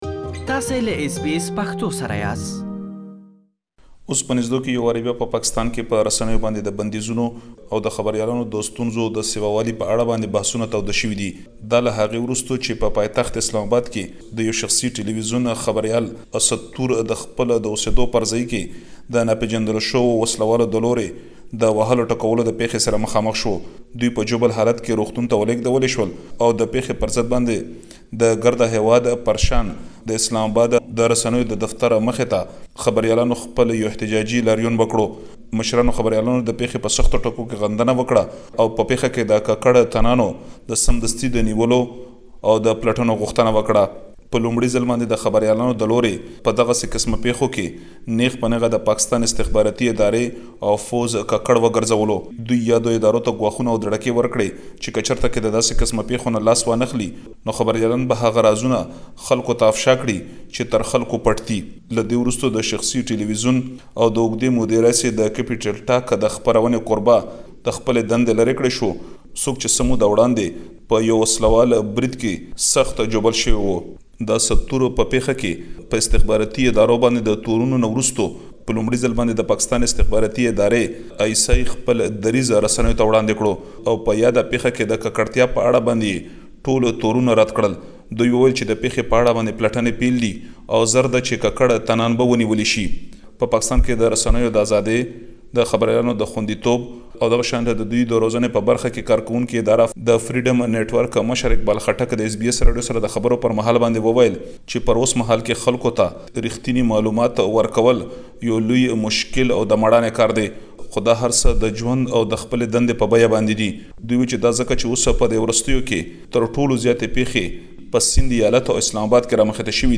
اس بي اس پښتو